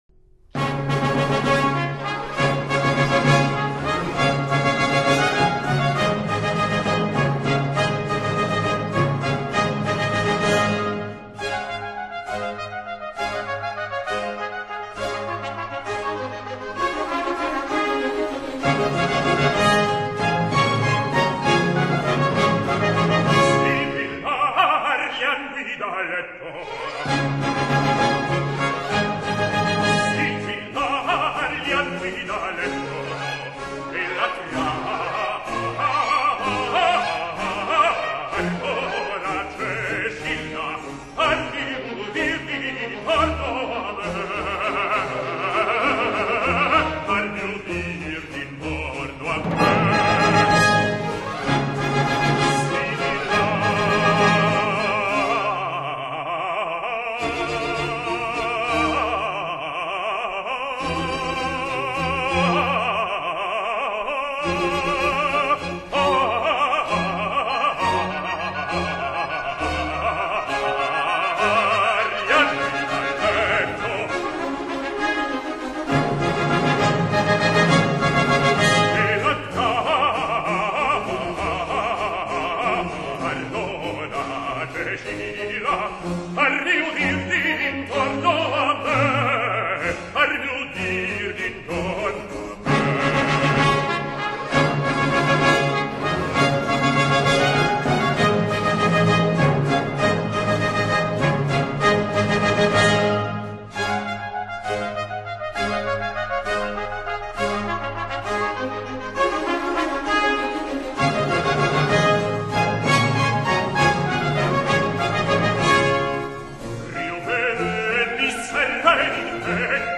Prepare yourself for some high opera!